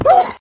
Sound / Minecraft / mob / wolf / hurt1.ogg
hurt1.ogg